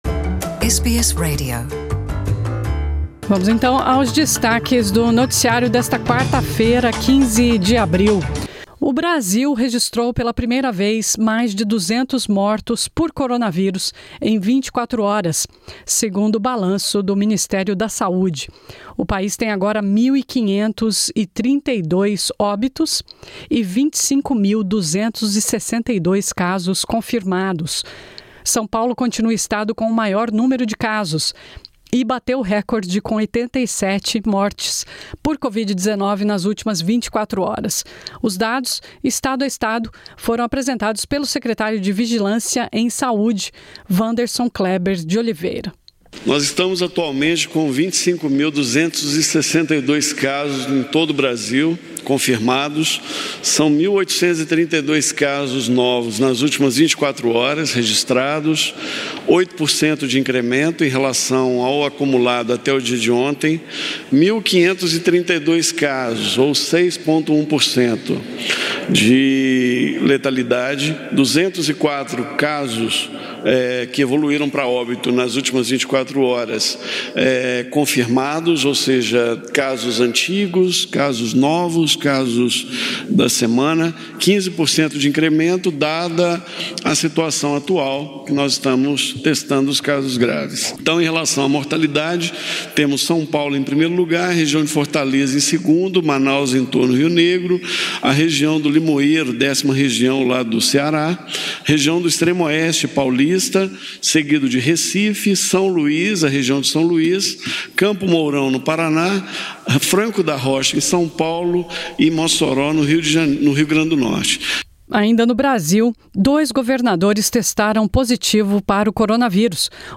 O Brasil registrou pela primeira vez mais de 200 mortos por coronavírus em 24 horas. São Paulo bateu recorde com número de mortes em um dia (87) e dois governadores testaram positivo para o coronavírus. Ouça essa e outras notícias do noticiário de hoje.